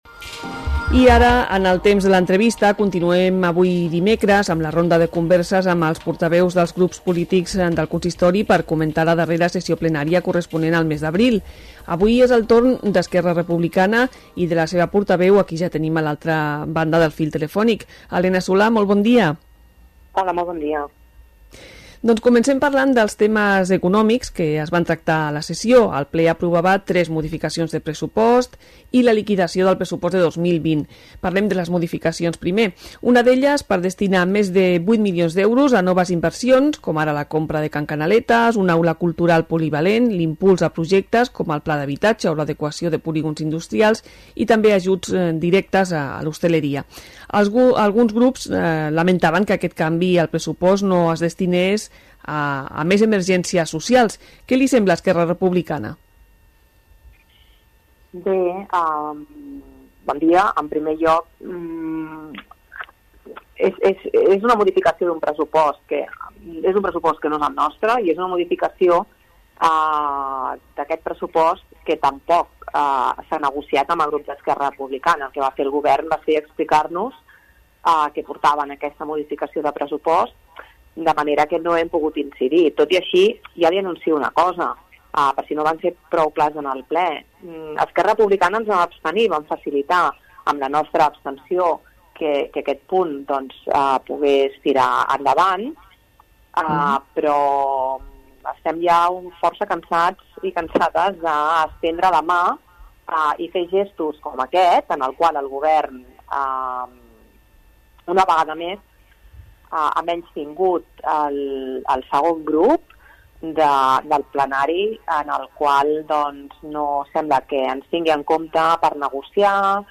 Entrevista-Helena-Solà-ERC.mp3